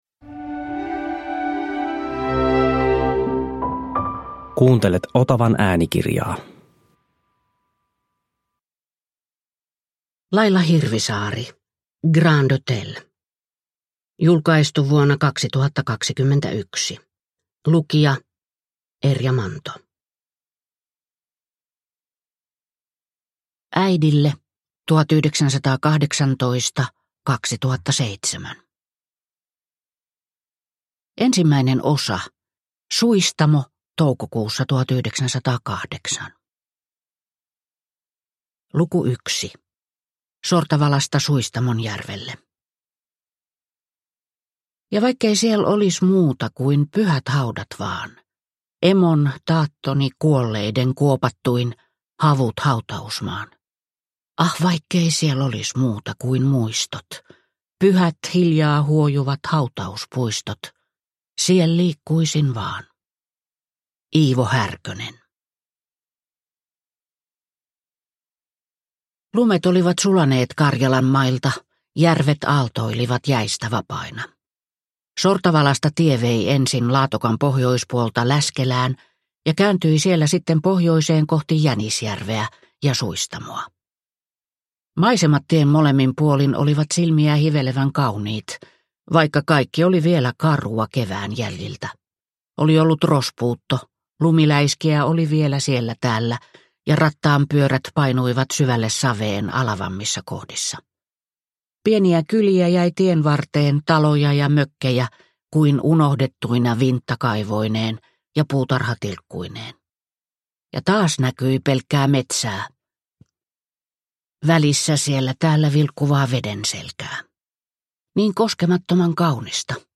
Grand hotel – Ljudbok – Laddas ner